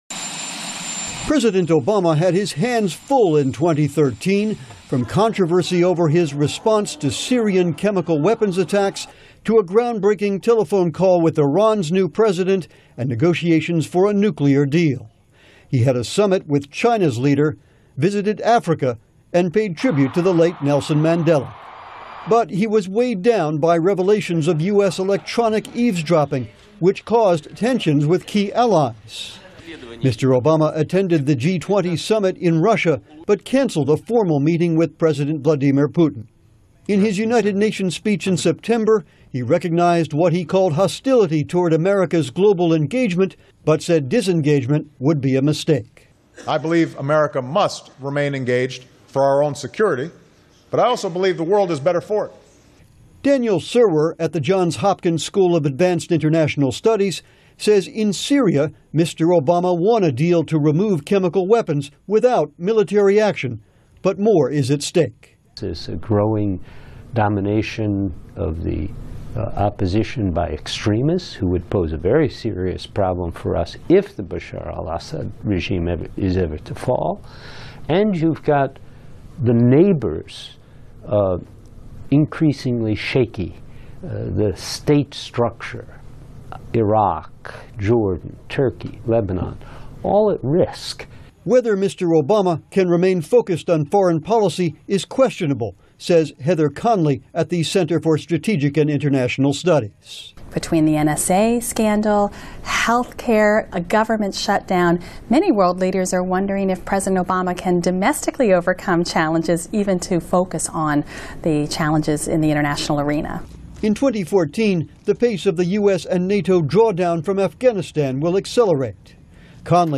本栏目是VOA常速英语，并附上了中文翻译以方便大家的学习。